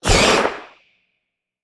Sfx_creature_spinner_flinch_01.ogg